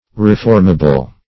Definition of reformable.
Reformable \Re*form"a*ble\ (r?*f?rm"?*b'l), a.